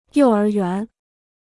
幼儿园 (yòu ér yuán) Free Chinese Dictionary